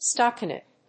発音記号
• / stὰkənét(米国英語)